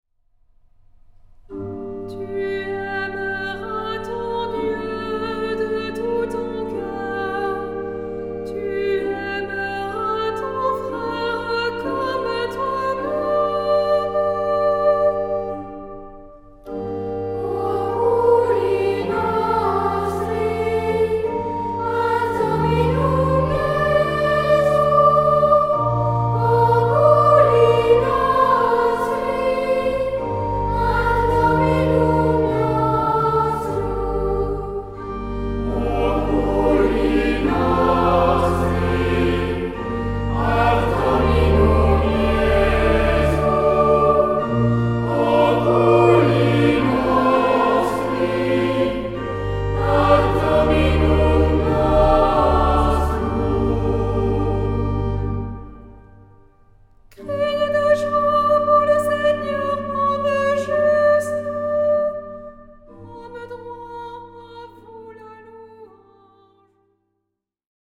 SAH O SATB (4 voces Coro mixto) ; Partitura general.
Salmodia.